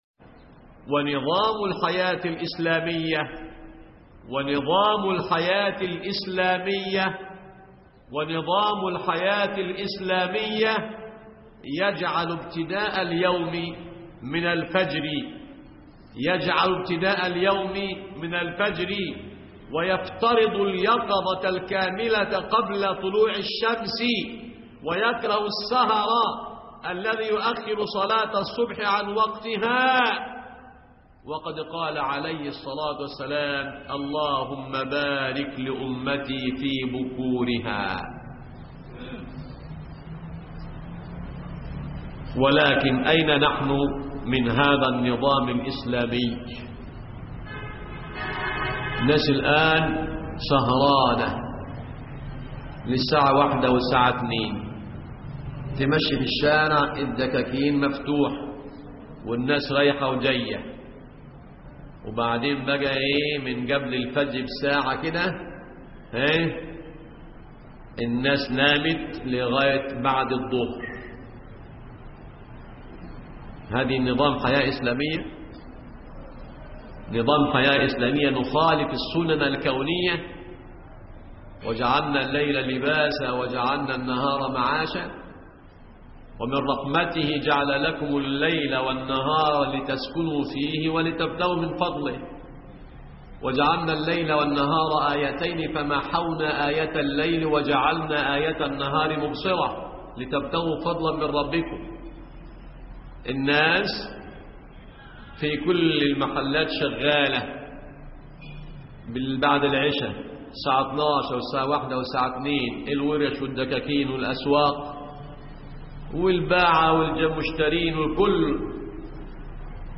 دروس إعتكاف 1435 هـ